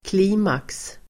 Uttal: [kl'i:maks]